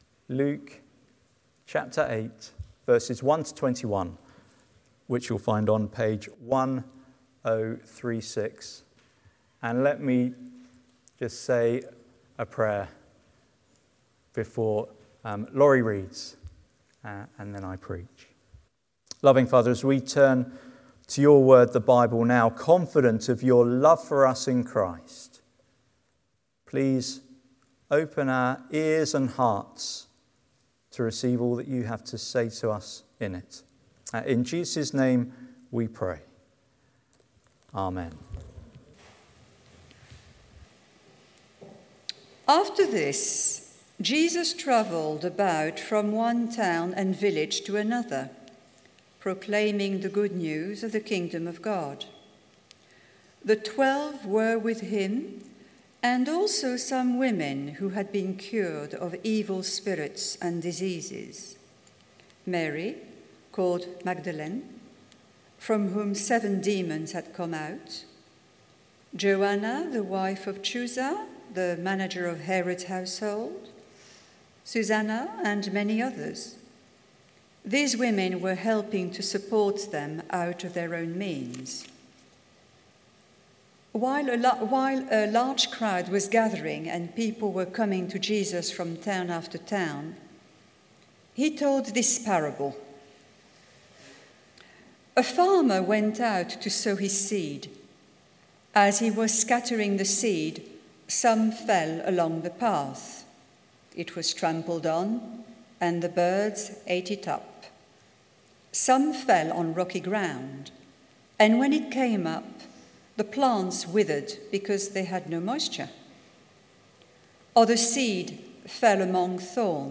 Sermon Transcript Study Questions